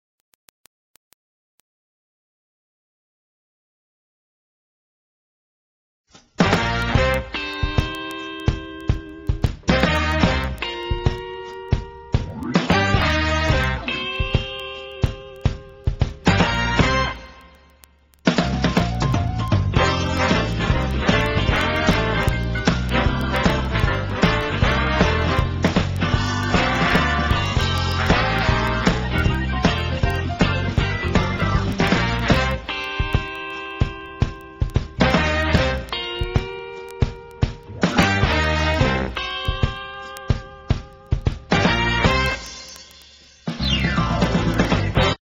NOTE: Background Tracks 9 Thru 16